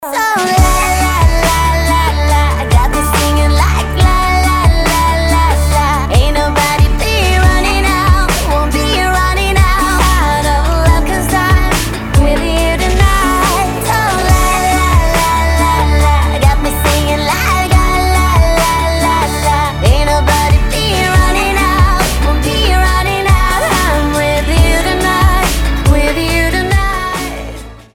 • Качество: 320, Stereo
поп
позитивные
озорные
игривые